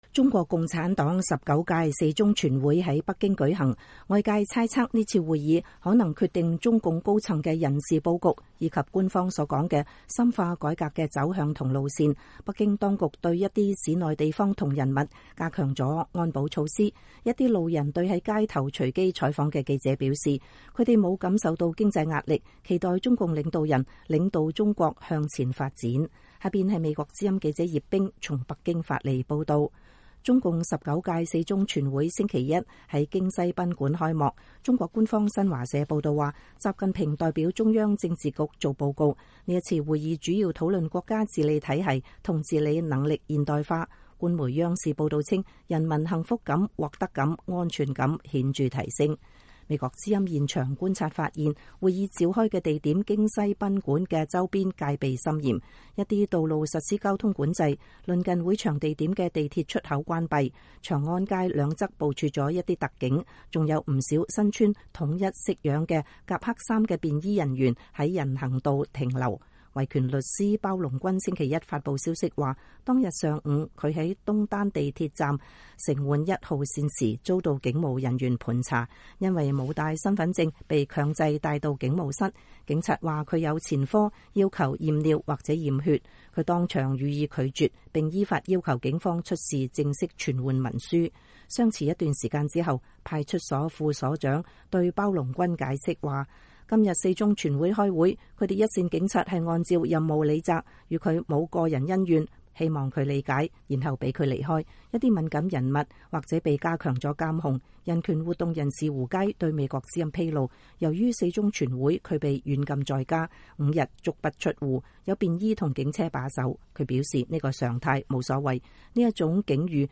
美國之音近日在北京街頭採訪了一些路人。
一位去影院觀看國慶七十周年電影的女士說：“國家會制定利於中國發展的方針政策，我們當然期待它越來越好了。